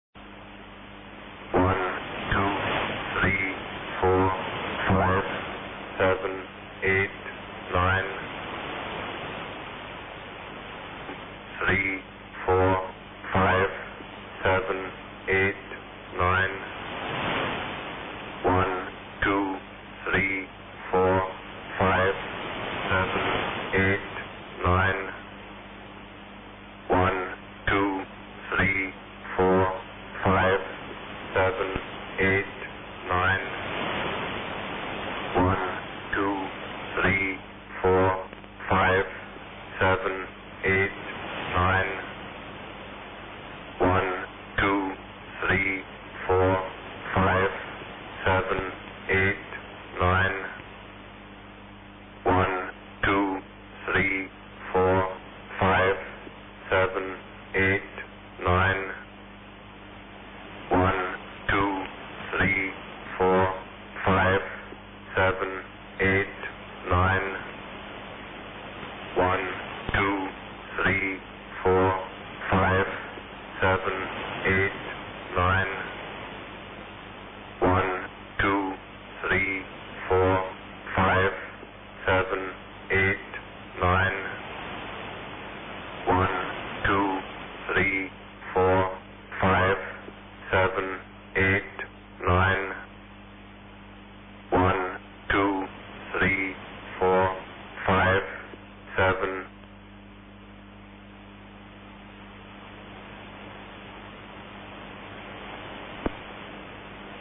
Начало » Записи » Номерные станции